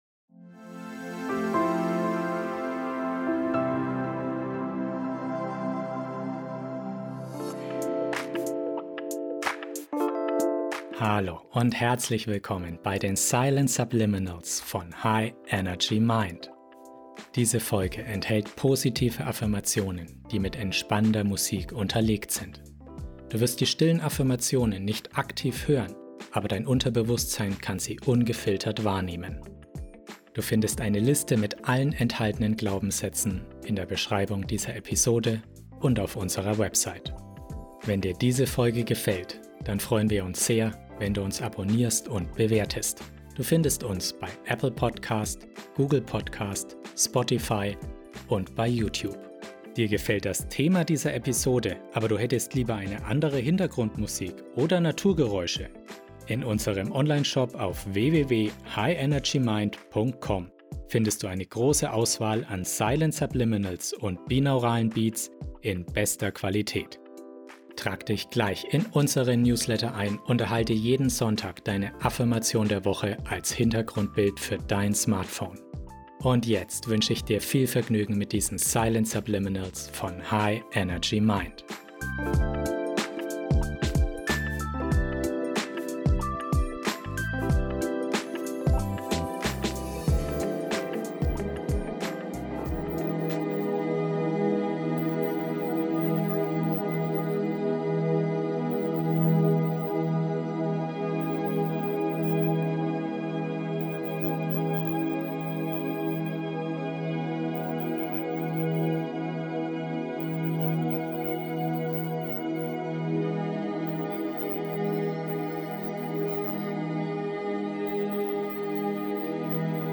Über diese Folge In dieser transformativen Episode im Silent Subliminals Podcast entdeckst du die Kraft der positiven “ICH BIN” Affirmationen. Die beruhigende 432 Hz Musik begleitet kraftvolle Silent Subliminals, die dein Unterbewusstsein erreichen und deine positiven Überzeugungen verstärken.